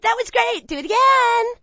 that_great_do_again_2.wav